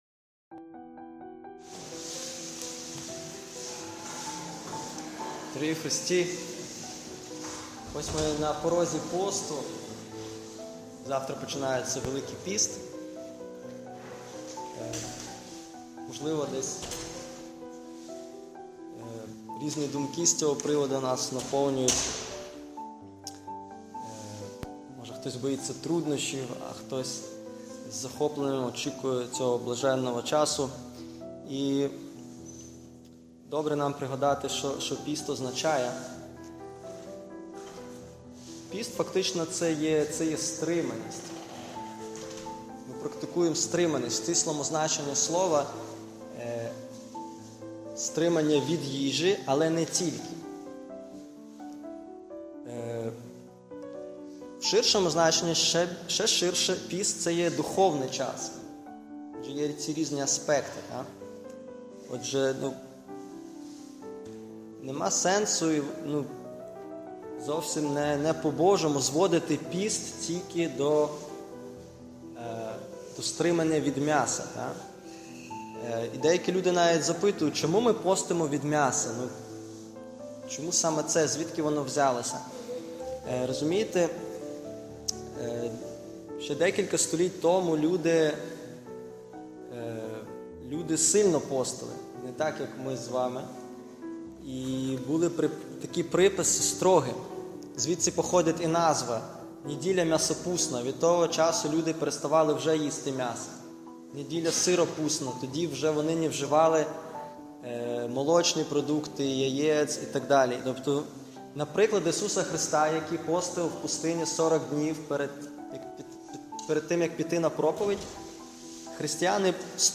Проповіді